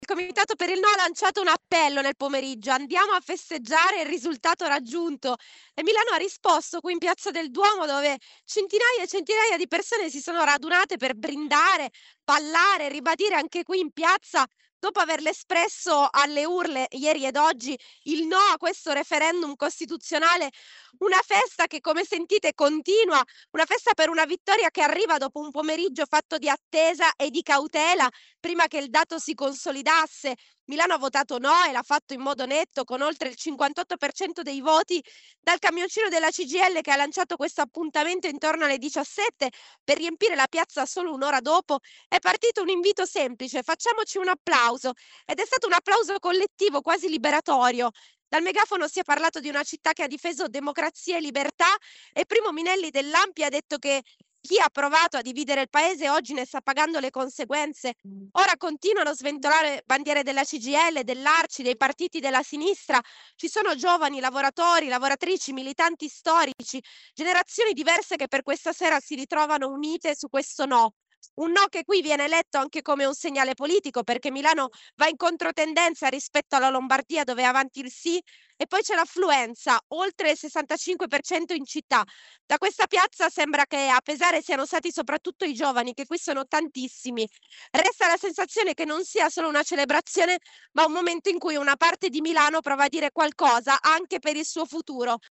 In serata la festa in piazza Duomo, con tanti giovani: “Oggi l’analisi della sconfitta la fanno gli altri”.